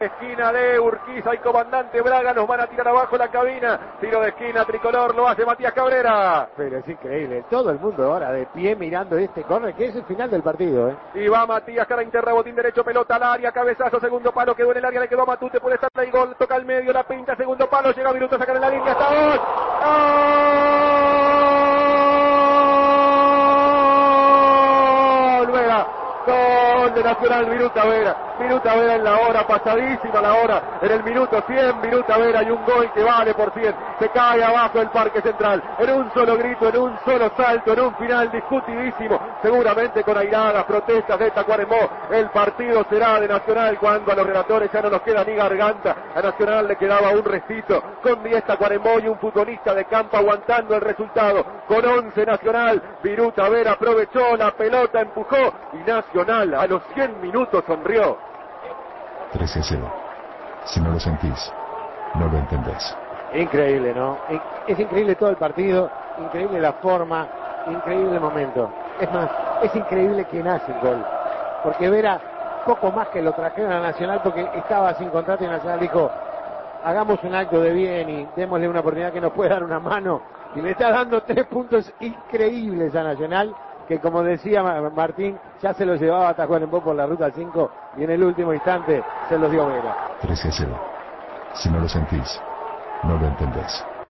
Relatos